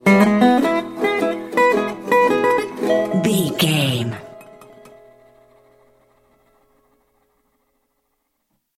Ionian/Major
acoustic guitar
electric guitar
ukulele
slack key guitar